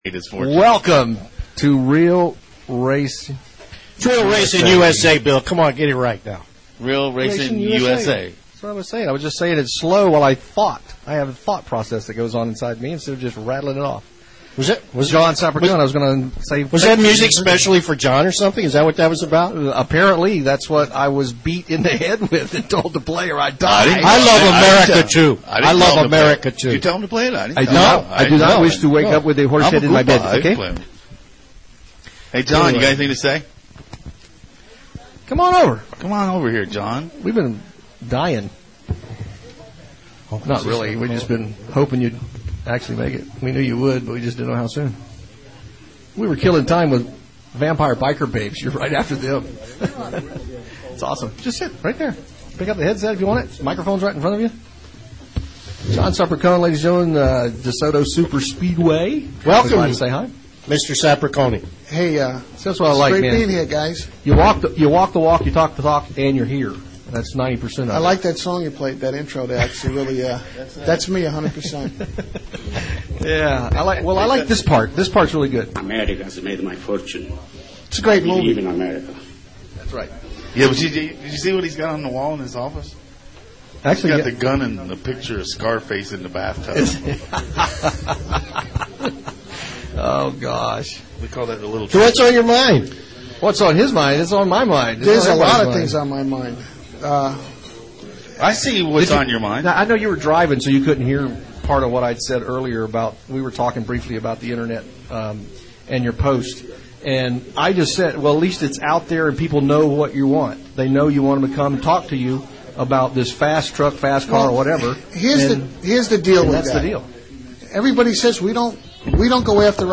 The IFR listening audience continues to grow every week and visits to the insane asylum are going to happen from time to time in an entertaining live racing show.